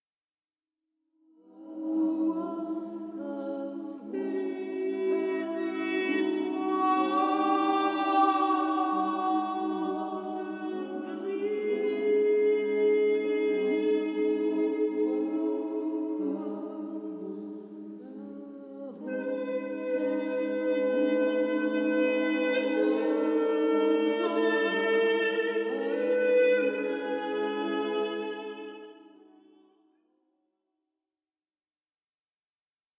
célèbre ensemble vocal
les voix des chanteuses